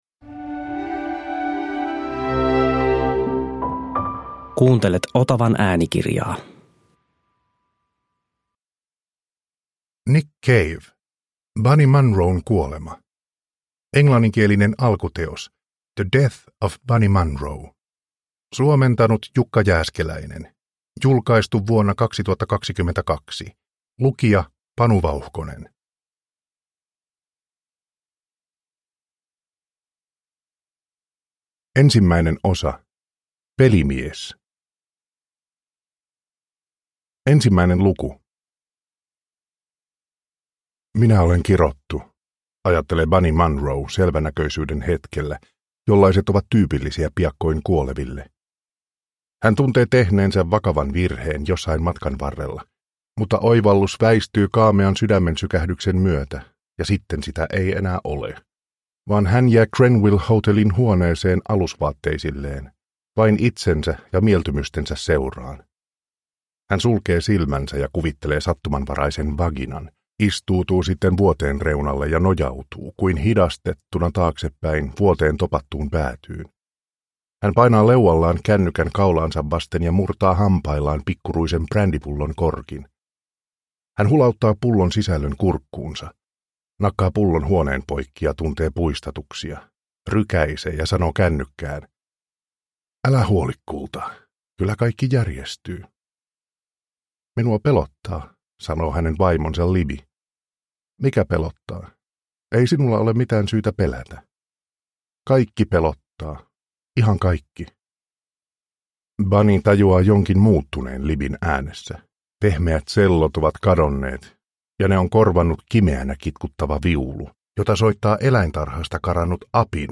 Bunny Munron kuolema – Ljudbok – Laddas ner